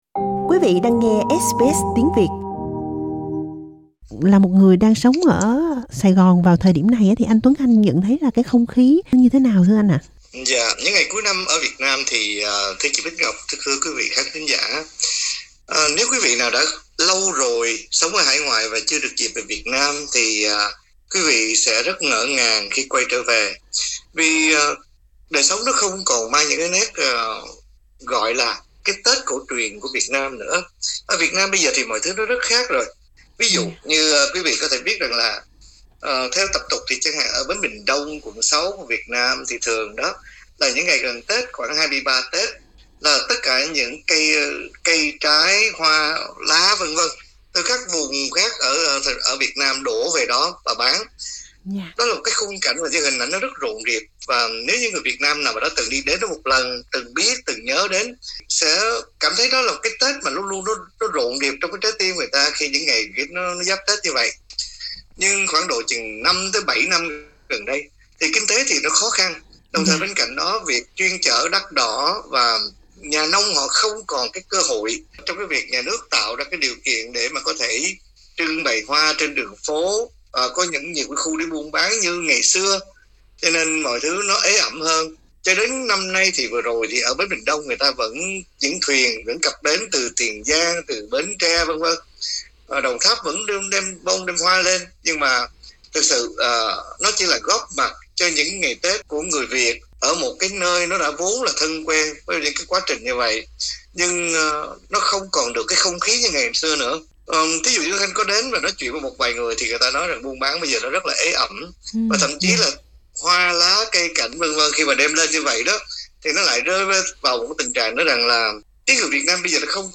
Tết xưa còn đâu… Cuộc trò chuyện